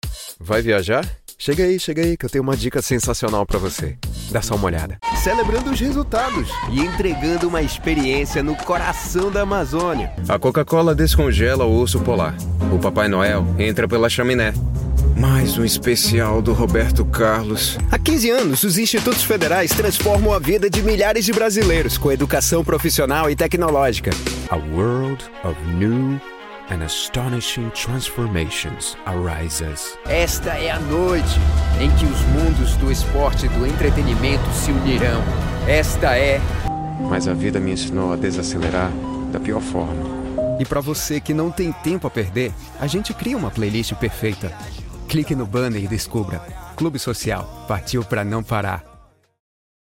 Home studio, doing this for a living since 2022, professional equipaments.
Brazilian Voiceover Talent.
Sprechprobe: Werbung (Muttersprache):